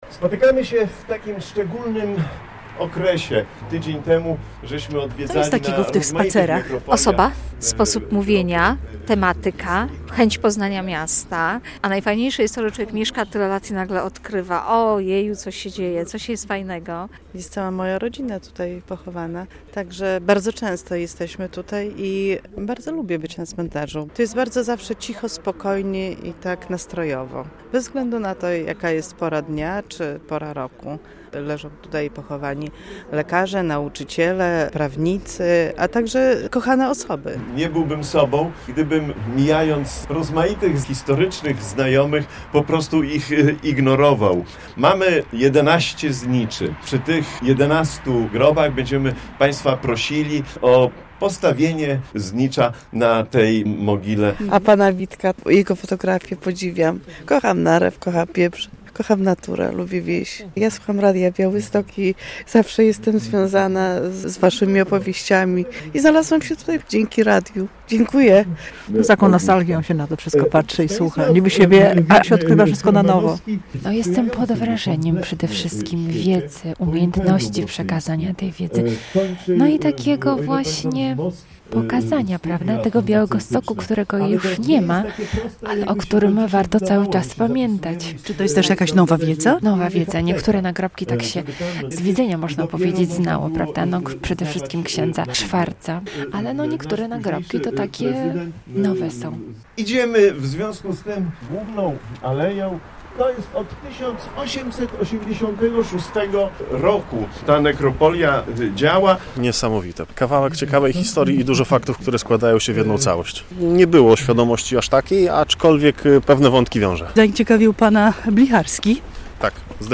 relacja
Przez dwie godziny w sobotę (9.11) wieczorową porą odwiedzaliśmy groby najznakomitszych postaci i zapalaliśmy światełko pamięci na grobach lekarzy, dziennikarzy, samorządowców, prezydentów czy artystów. Najbardziej zasłużeni spoczywają na "białostockich Powązkach", czyli cmentarzu farnym.